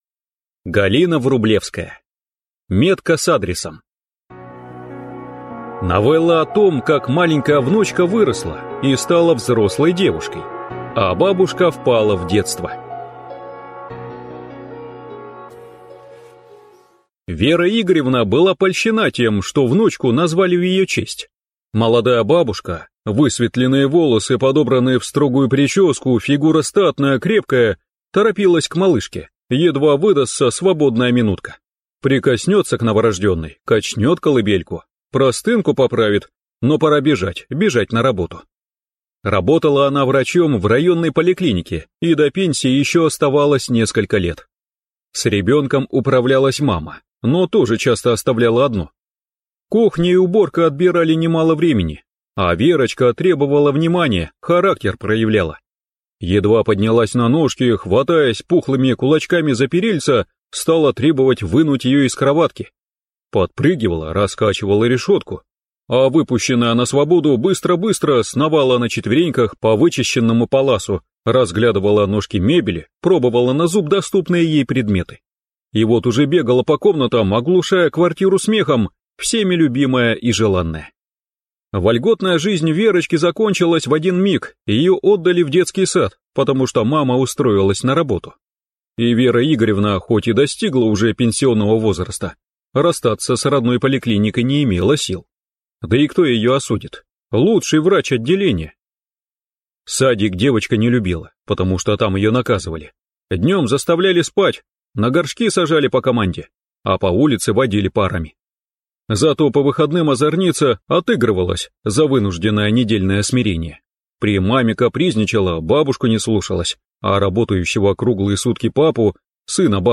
Аудиокнига Метка с адресом | Библиотека аудиокниг